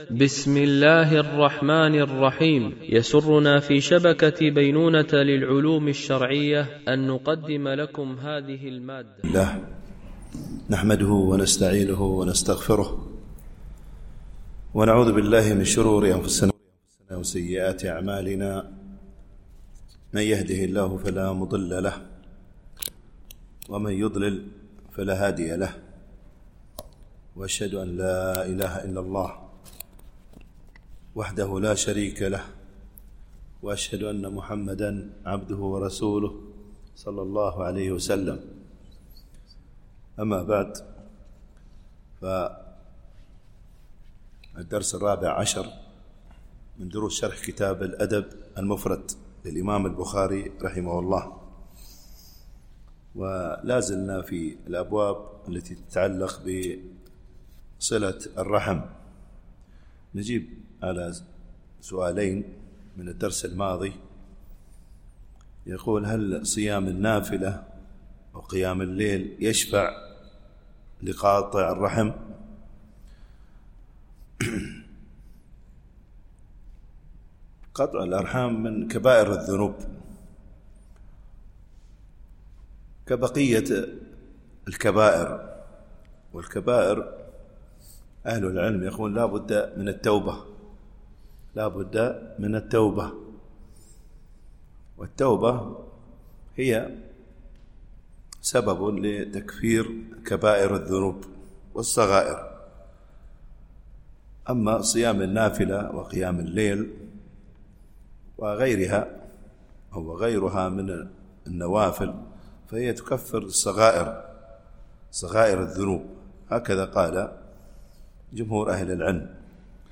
الشرح الثاني للأدب المفرد للبخاري - الدرس14 ( الحديث 52 -53 )